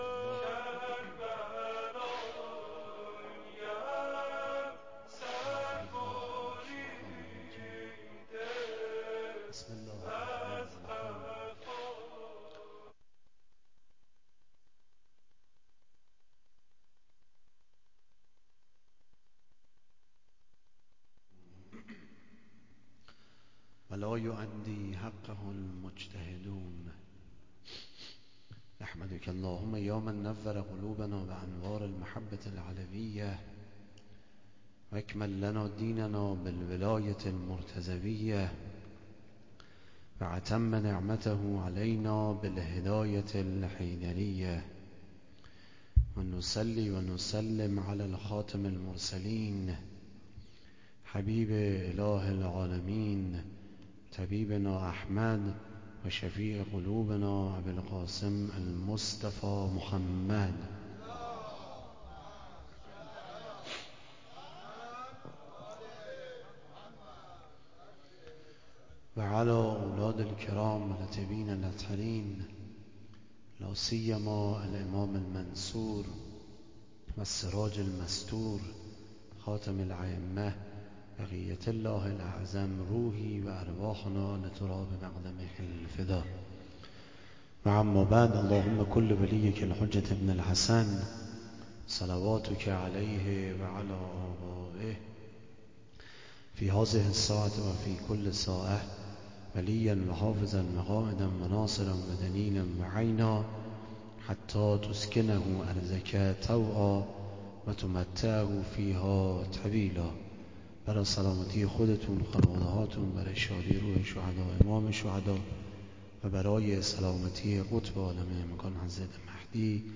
سخنرانی
در شب پنجم محرّم96 در بارۀ علّت خلقت و اهمّیّت عزاداری سیدالشّهداء(ع)